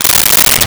Stapler Opened 01
Stapler Opened 01.wav